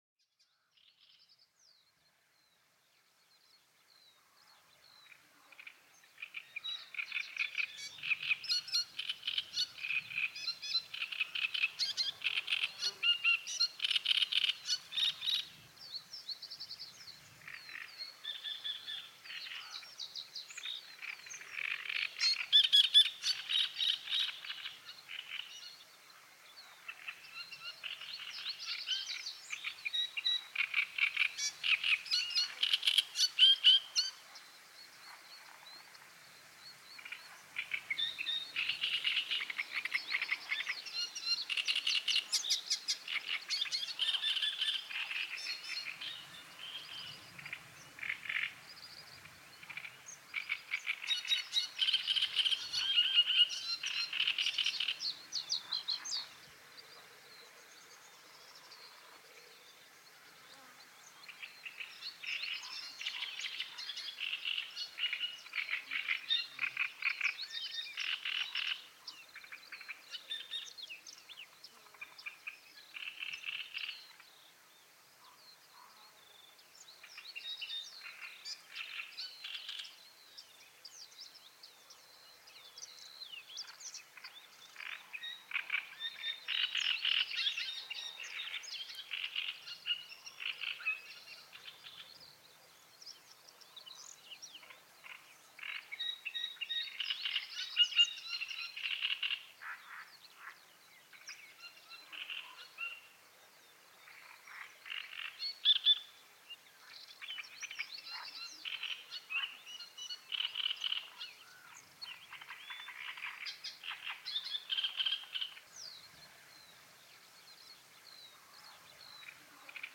Les chants apaisants des oiseaux pour un sommeil profond.
Laissez-vous bercer par les chants doux et mélodieux des oiseaux en pleine nature. Ces sons apaisants favorisent une relaxation profonde et un sommeil réparateur.
Chaque épisode combine les sons de la nature et de la musique douce pour favoriser le sommeil et la détente.